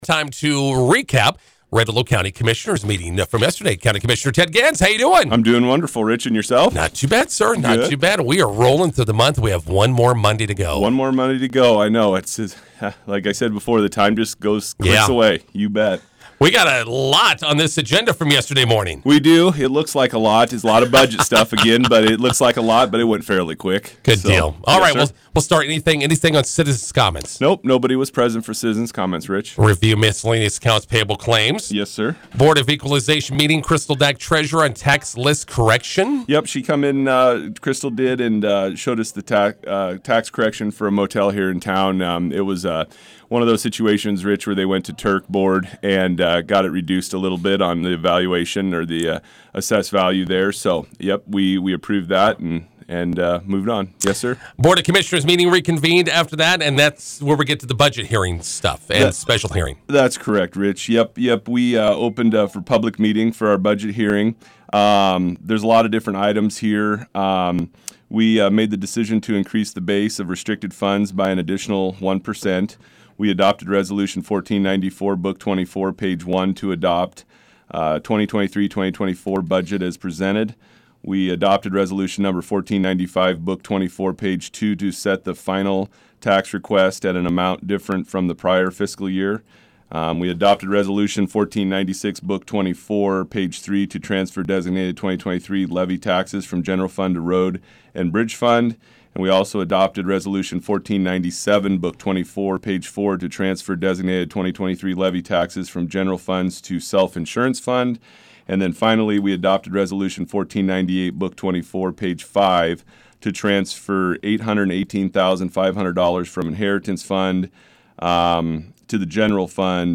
INTERVIEW: Red Willow County Commissioners meeting with County Commissioner Ted Gans.